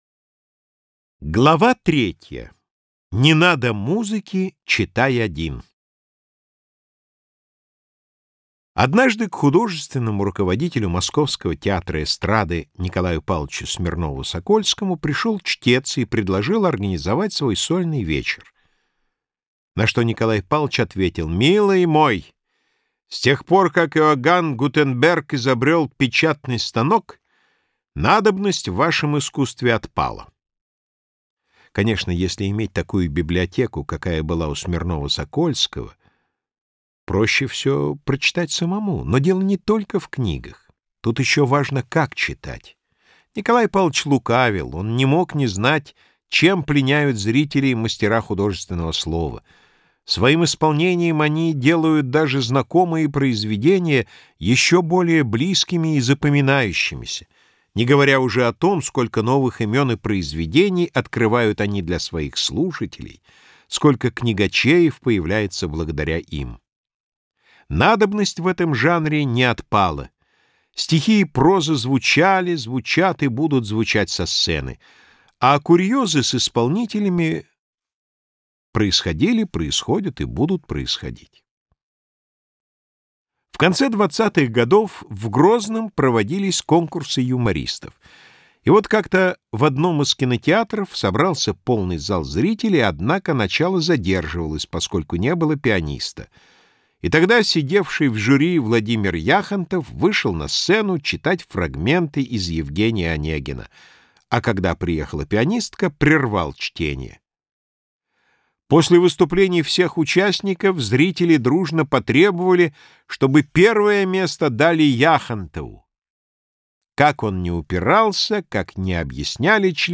Аудиокнига Райкин, Ширвиндт, Задорнов и все-все-все в забавных историях | Библиотека аудиокниг